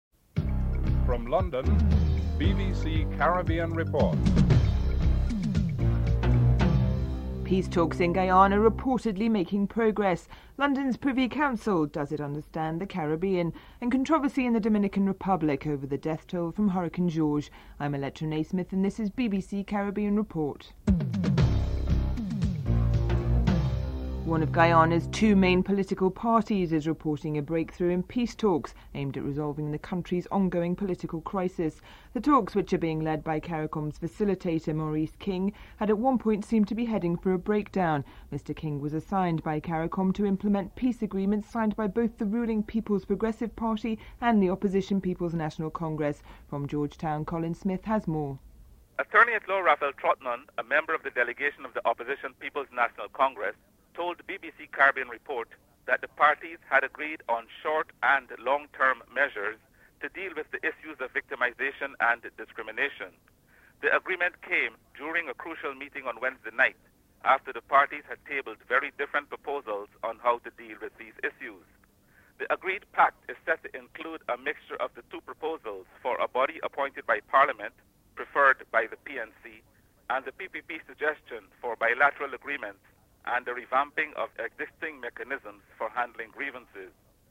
The British Broadcasting Corporation
1. Headlines (00:00-00:22)